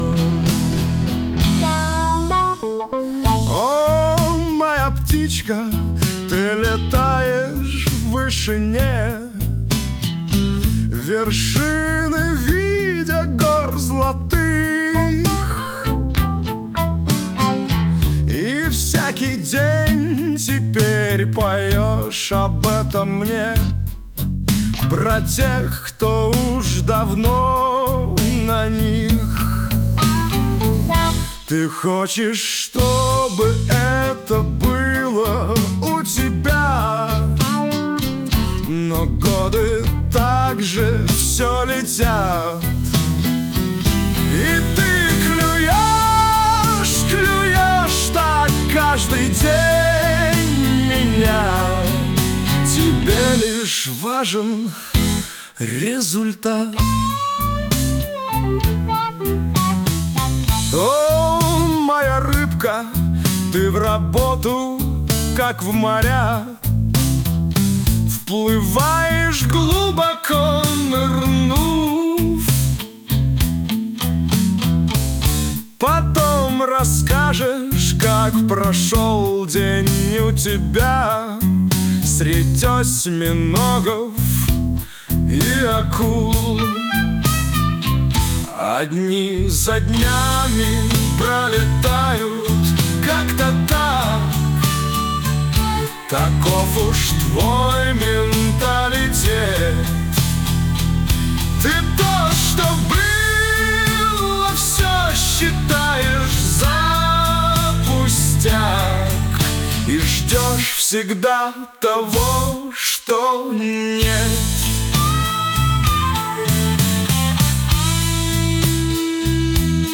• Alternative versions: Рок